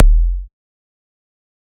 EDM Kick 45.wav